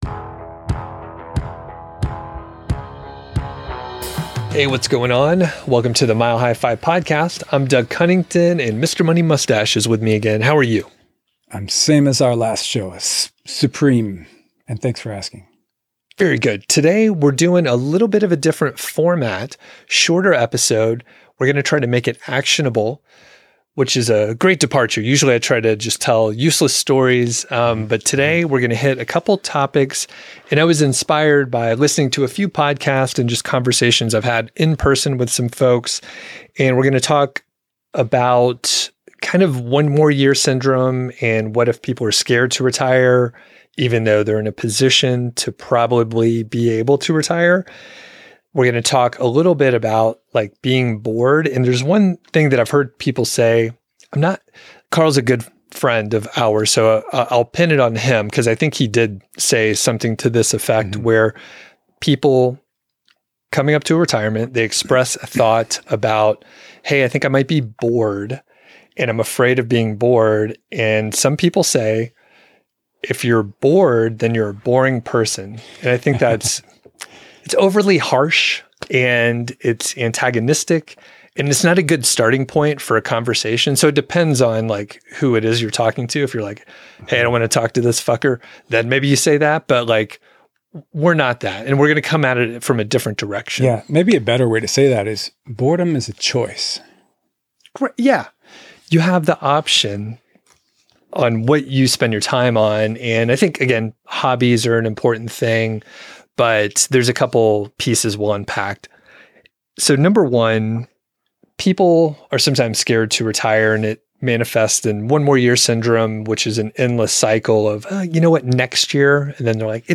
chats with Pete Adeney (Mr. Money Mustache) about various fears that some people have about early retirement. They explore why some people may be reluctant to retire, despite being financially ready, and specifically how fear of boredom can hold people back.